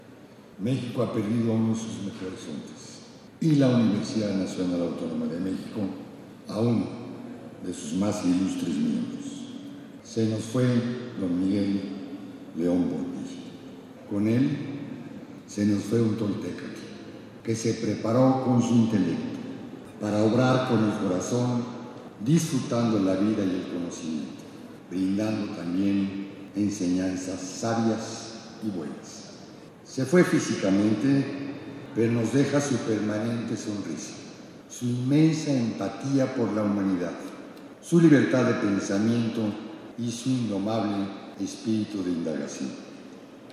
En el Palacio de Bellas Artes, señaló que su partida es como la de un verdadero artista o toltécatl, que de acuerdo con la tesis doctoral del también filólogo y filósofo, eran los sabios nativos, aquéllos que componen cosas, obran hábilmente, crean, hacen todo lo bueno y hermoso.